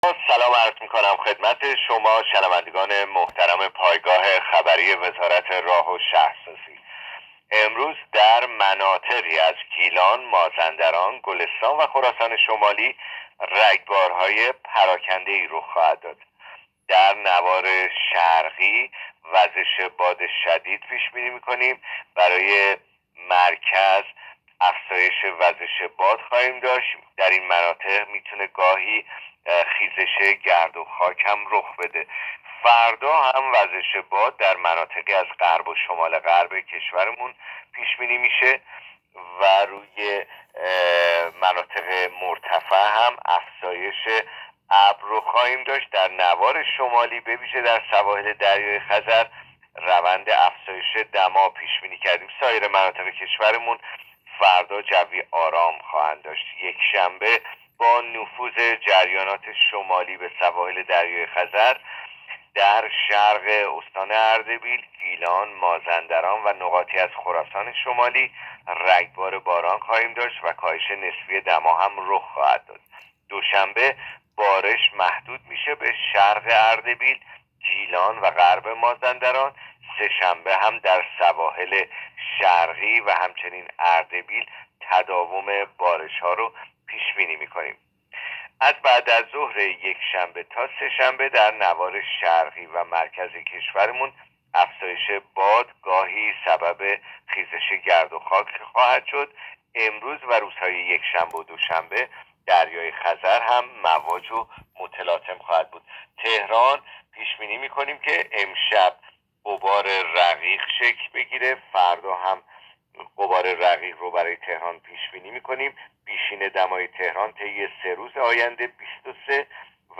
گزارش رادیو اینترنتی پایگاه‌ خبری از آخرین وضعیت آب‌وهوای نهم آبان؛